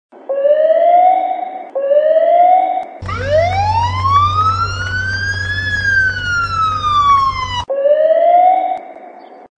policijska_sirena4640.mp3